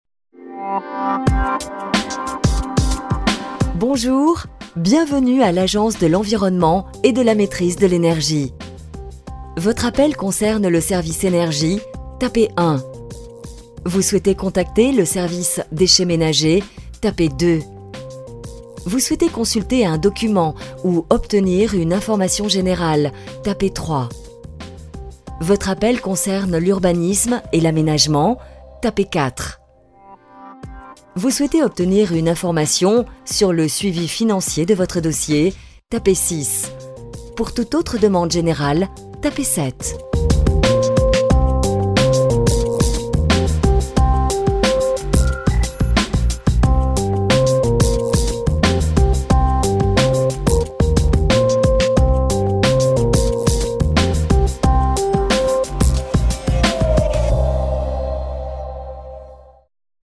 Sprecherin französisch (Muttersprachlerin) warm, smoth and secure, serious, friendly, smilee, fresh intentions
Sprechprobe: Sonstiges (Muttersprache):
Professional French native voice over: warm, smoth and secure, serious, friendly, smilee, fresh intentions